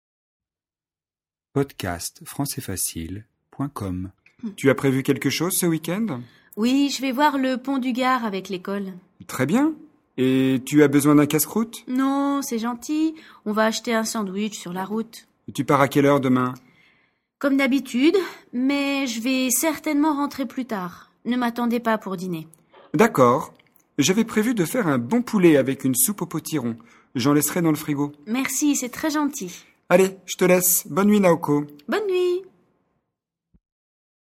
🔹DIALOGUE :